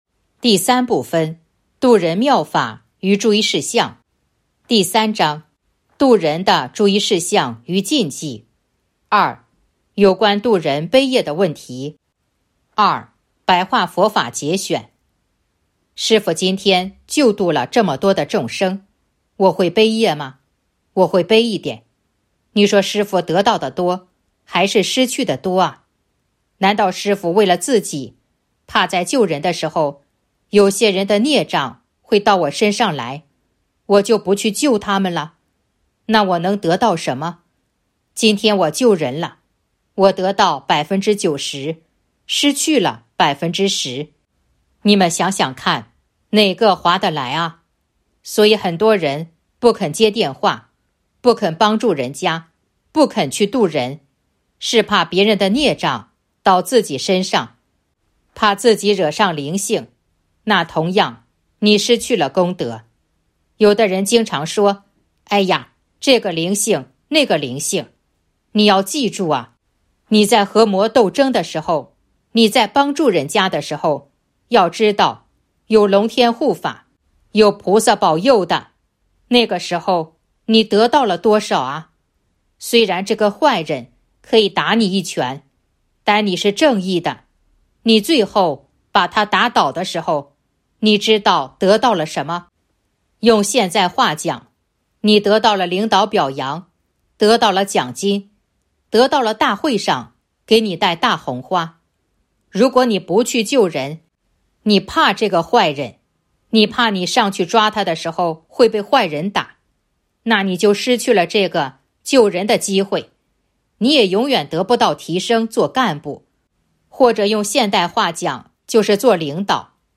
054.2. 白话佛法节选《弘法度人手册》【有声书】